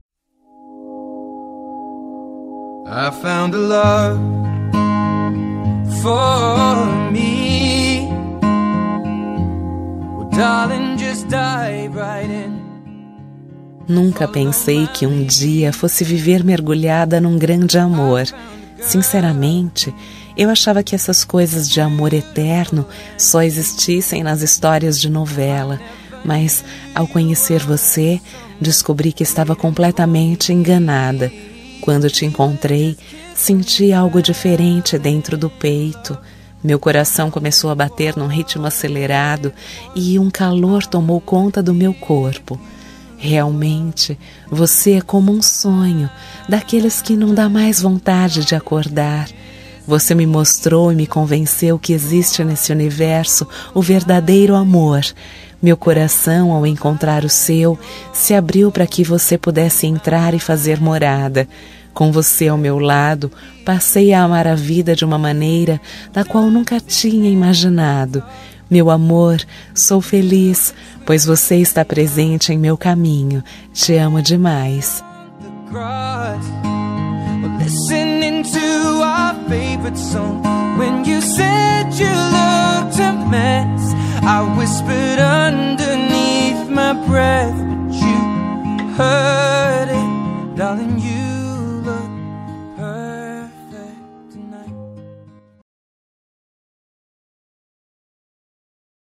Telemensagem Romântica – Voz Feminina – Cód: 566